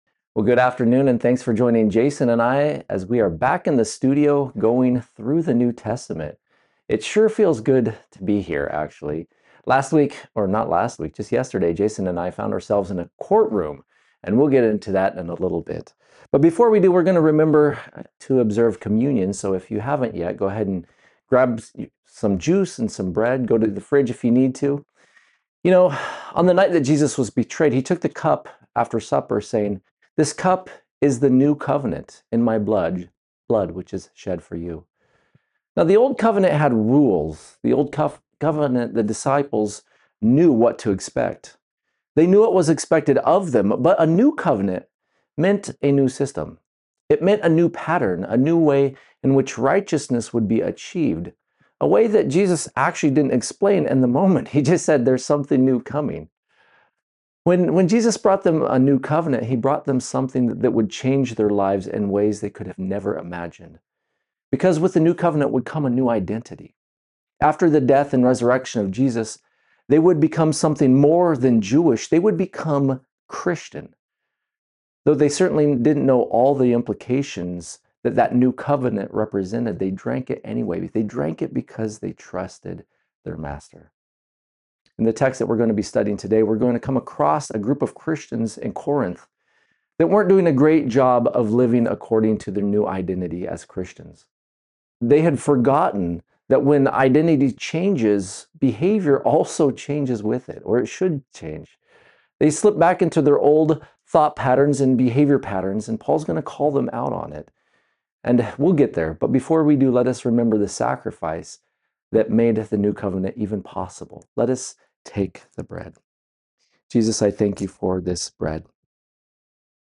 Bible Teachings
Because of this, our messages are prerecorded & streamed live for those who cannot join us in person.